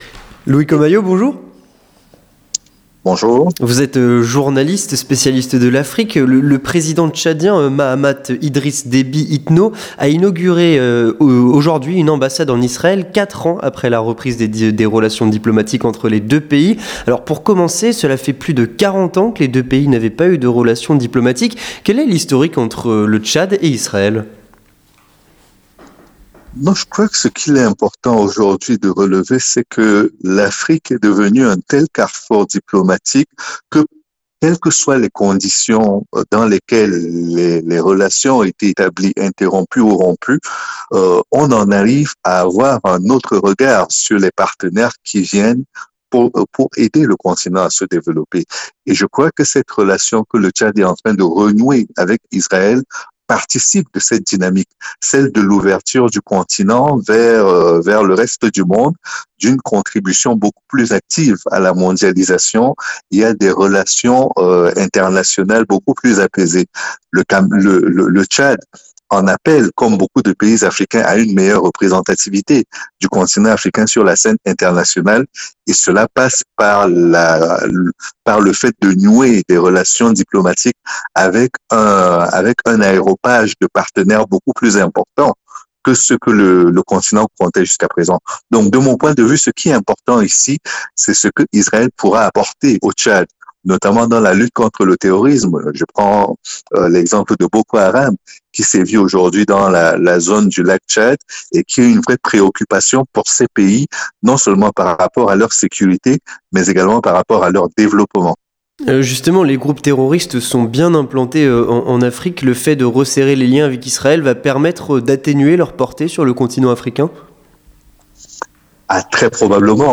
Entretien du Grand Journal (03/02/2023)